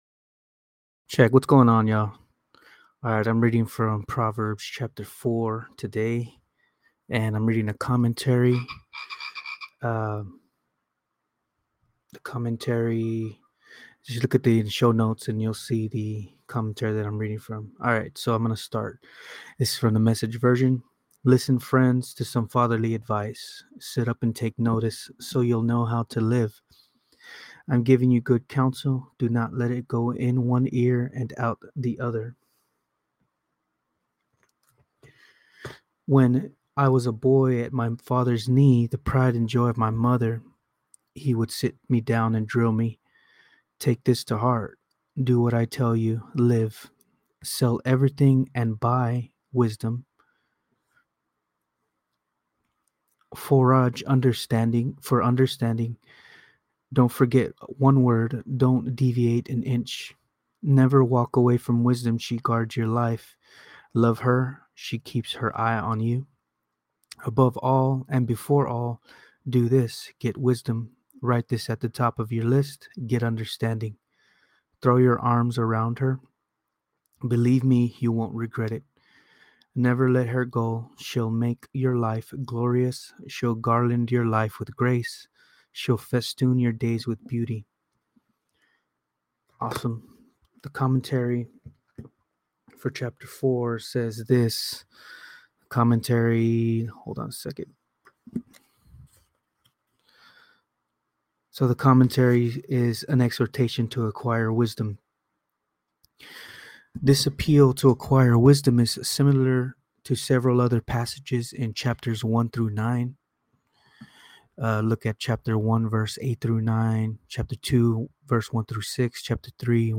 Proverbs 4 | Reading & Commentary